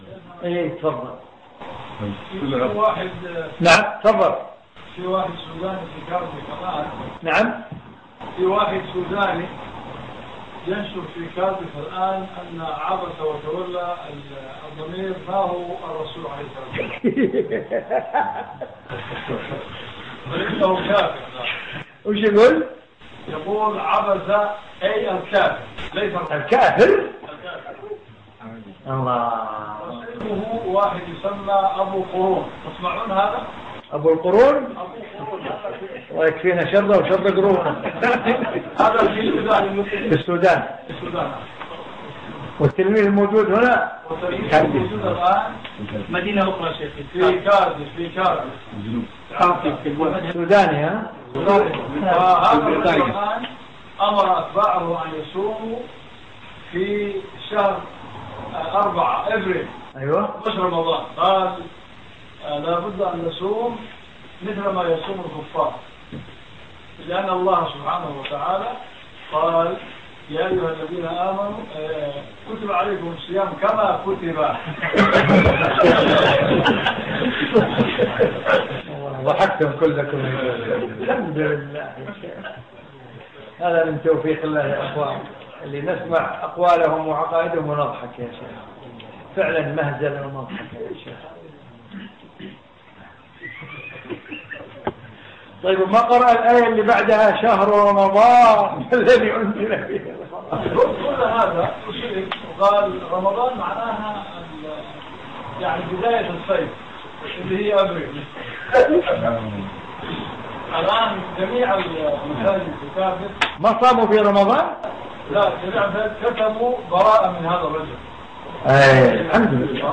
في مسجد الصحابة السلفي في مدينة برمنجهام البريطانية في 19 شوال 1435